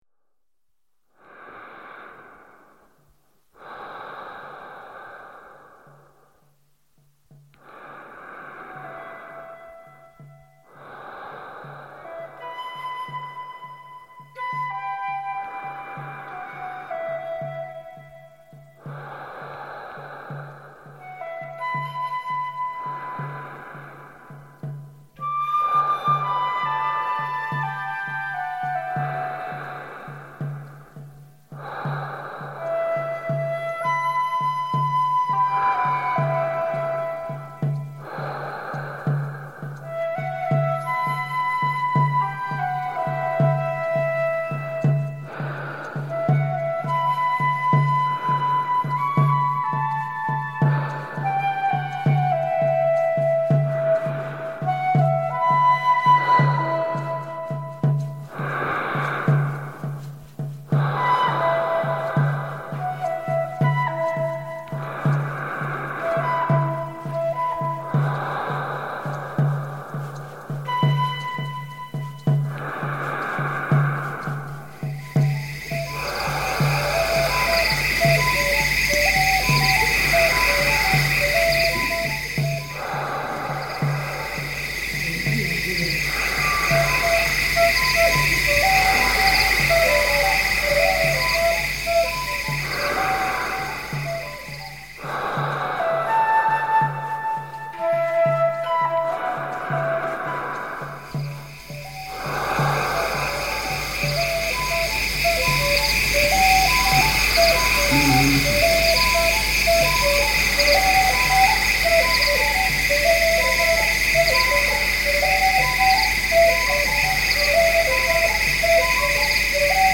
playing flutes in the forest at night
I decided to create a dreamscape.